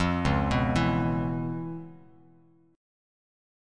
C#7#9 chord